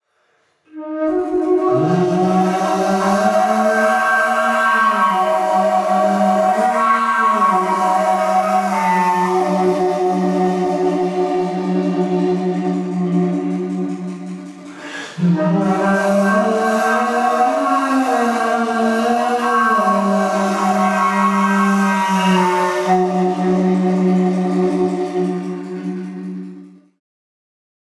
Electronic Organ
Kamanche